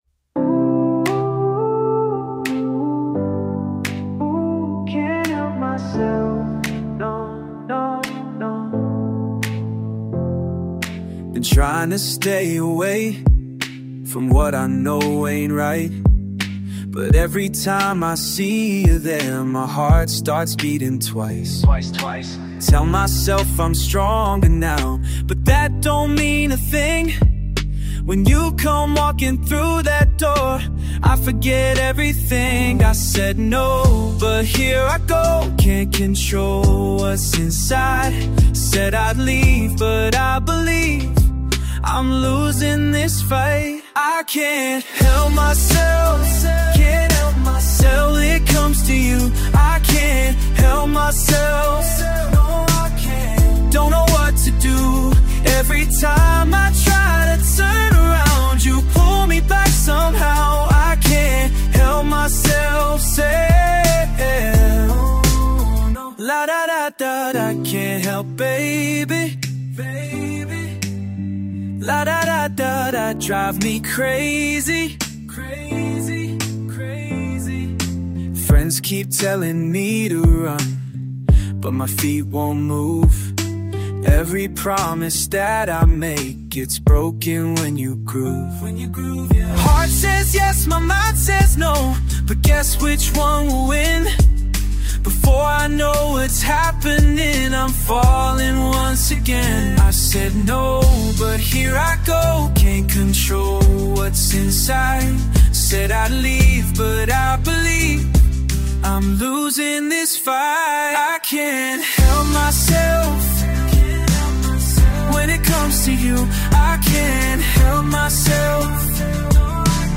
Sexy Male Voice